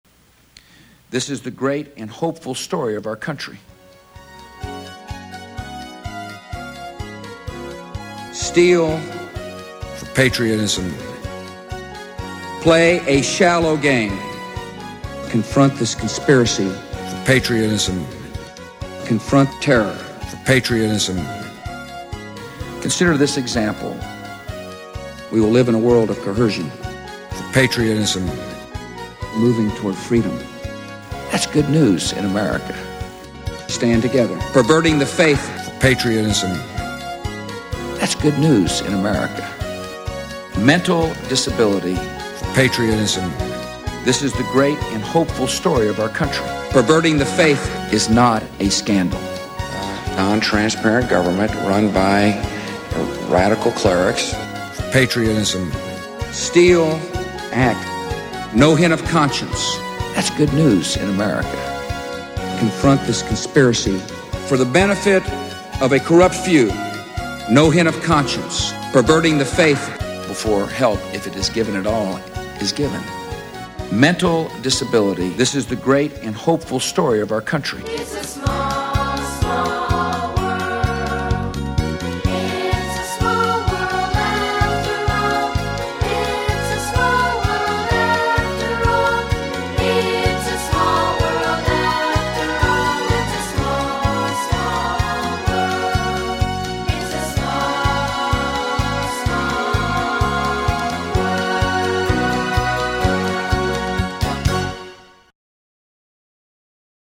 Creator: Ubuibi
A play on mispronunciation.